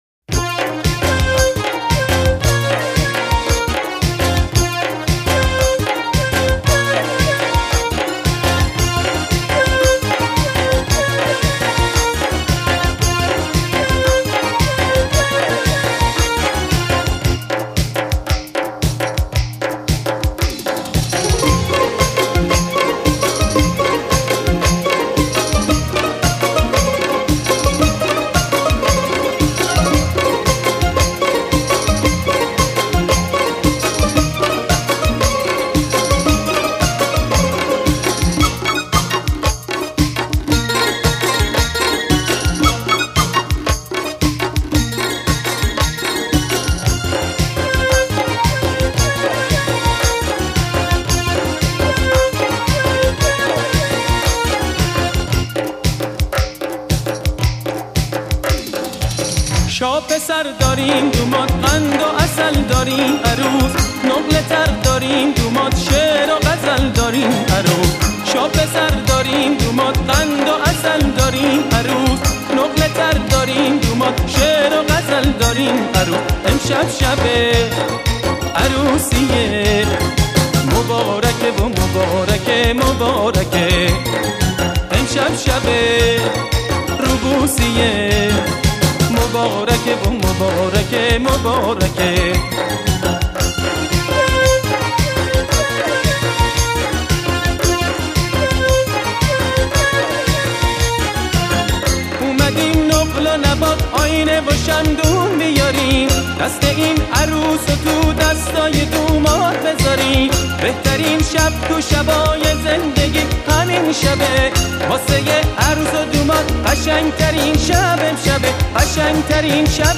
آهنگ شاد ایرانی